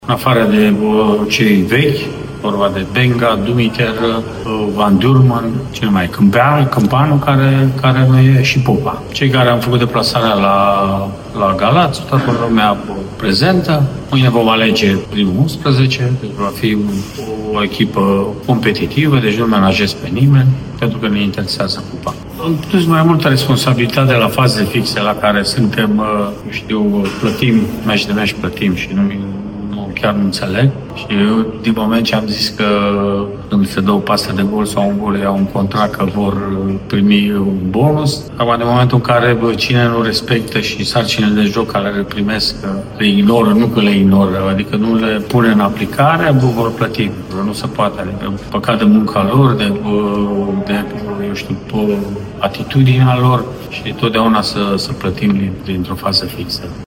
Mircea Rednic are așteptări de la echipa proprie și spune că, în afara accidentaților de lungă durată, nu sunt alți absenți: